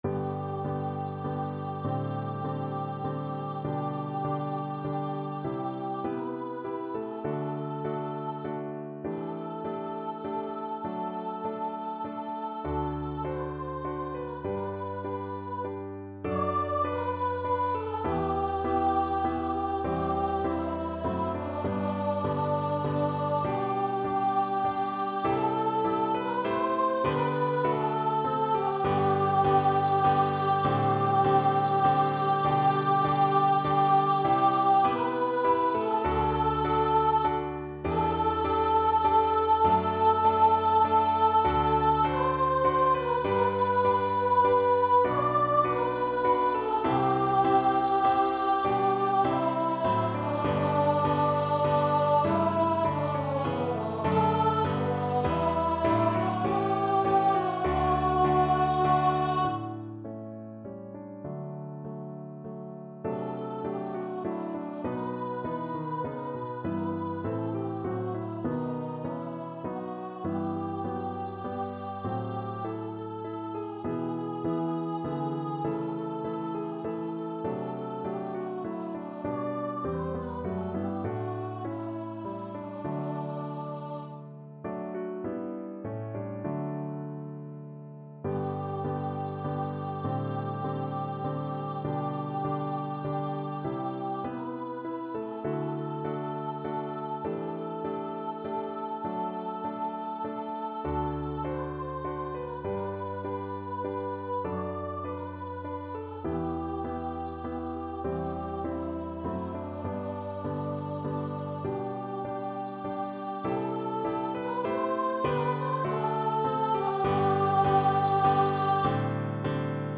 Free Sheet music for Voice
Voice
3/4 (View more 3/4 Music)
G major (Sounding Pitch) (View more G major Music for Voice )
Classical (View more Classical Voice Music)